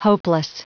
Prononciation du mot hopeless en anglais (fichier audio)
Prononciation du mot : hopeless